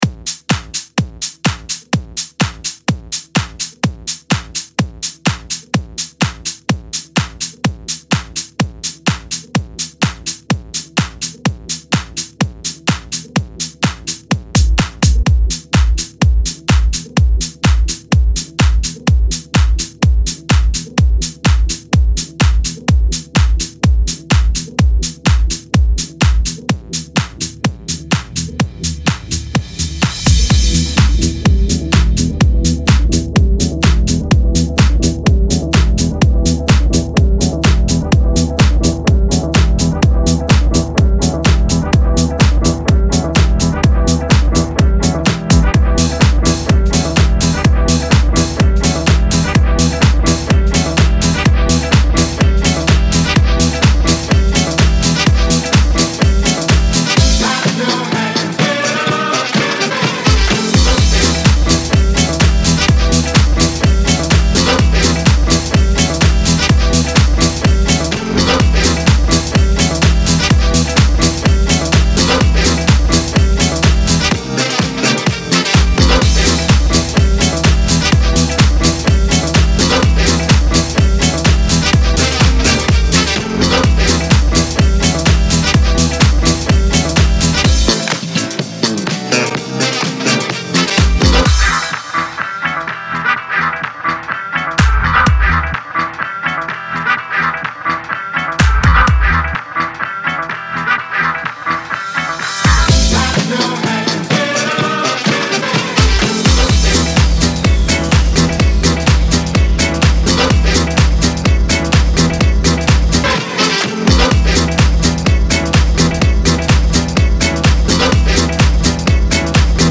Jackin House 2020 Mix